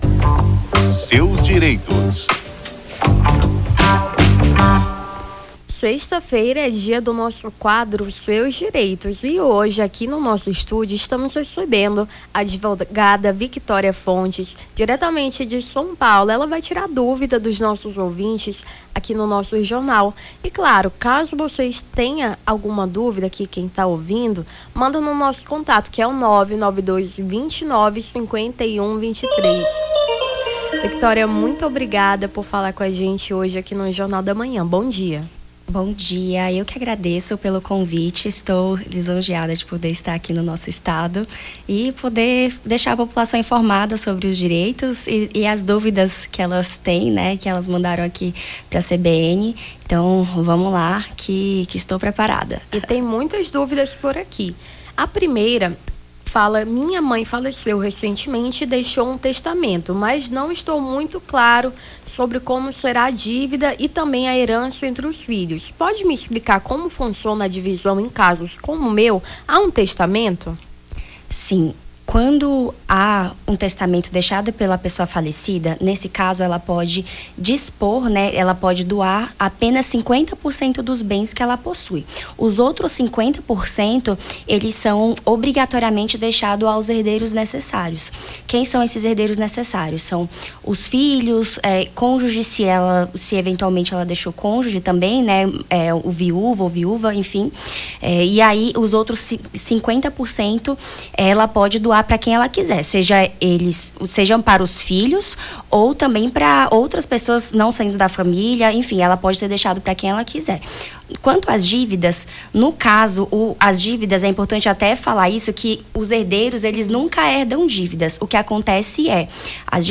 Seus Direitos: advogada tira dúvidas sobre direito de família